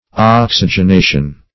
Oxygenation \Ox`y*gen*a"tion\, n. [Cf. F. oxyg['e]nation.]